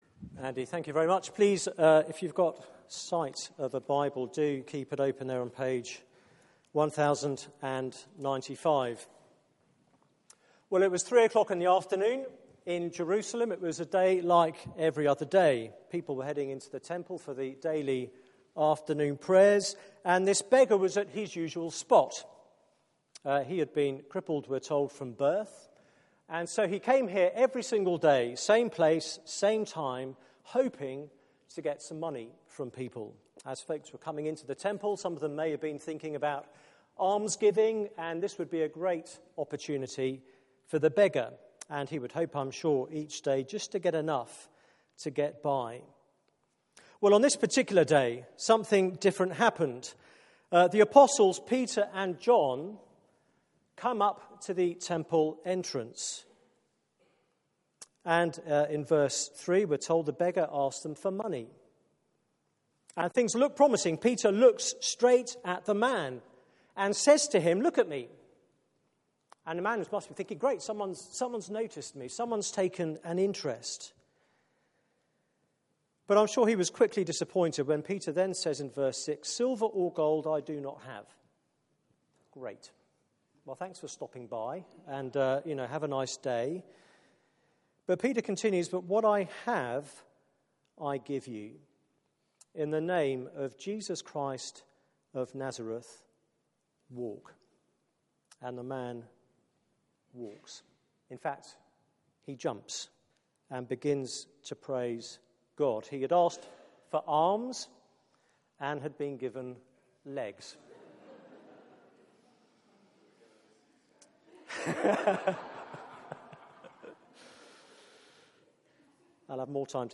Media for 6:30pm Service on Sun 27th Mar 2016 18:30 Speaker
Theme: Death could not hold him Sermon